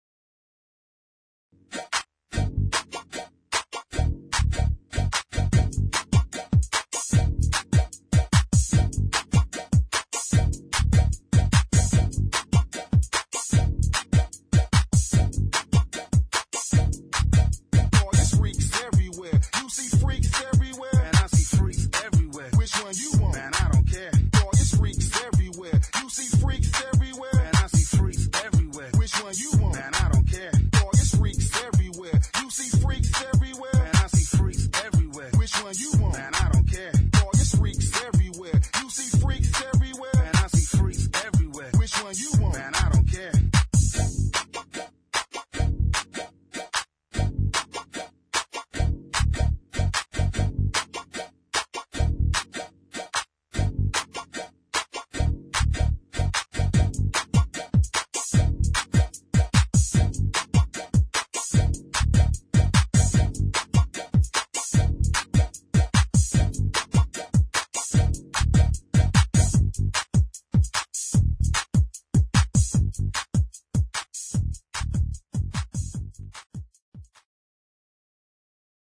[ GHETTO | ELECTRO ]
エレクトロでファンキーなゲットー・テック・ベース！